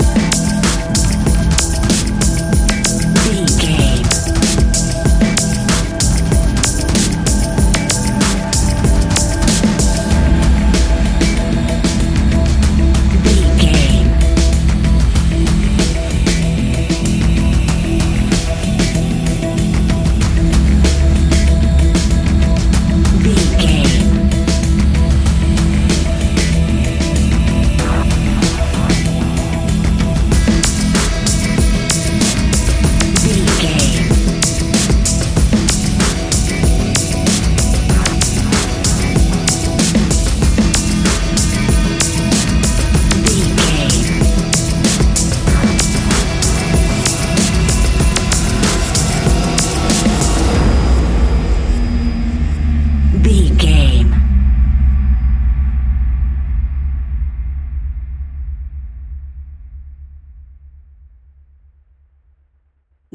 Fast paced
In-crescendo
Ionian/Major
dark ambient
EBM
drone
experimental
synths
instrumentals